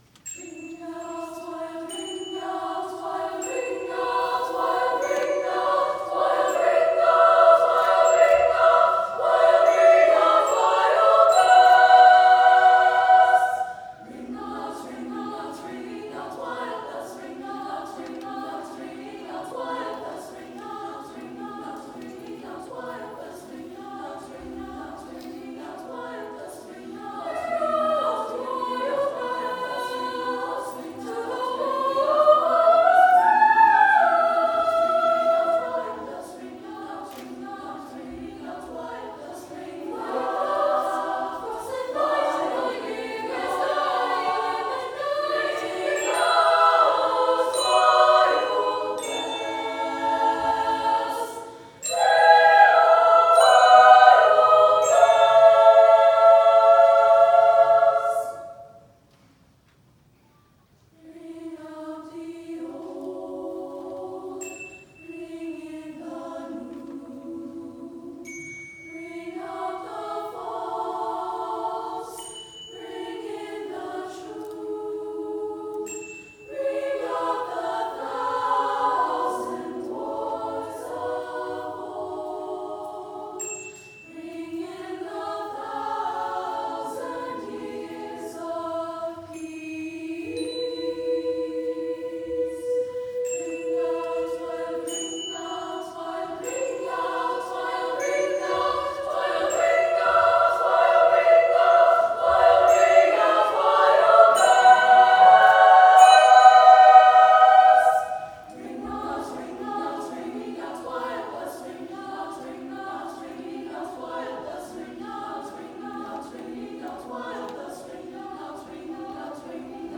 SSAA Chorus with Handbells